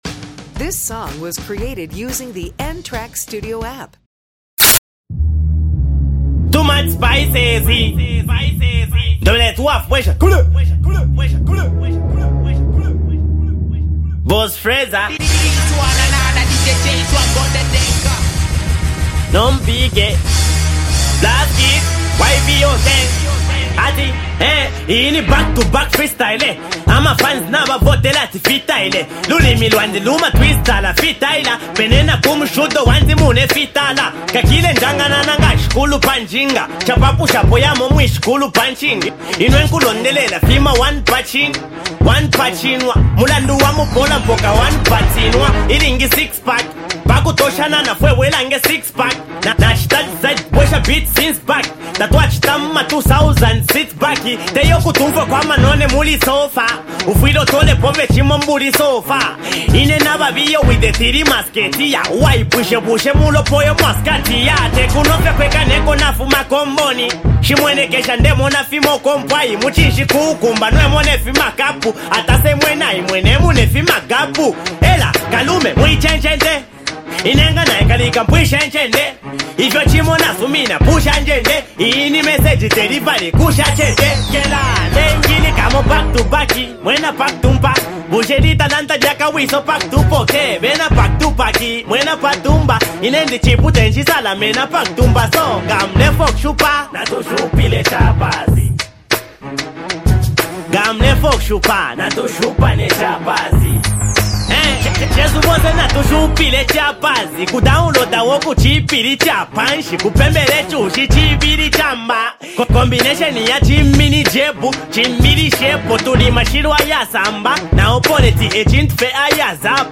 In this freestyle
aggressive delivery and sharp wordplay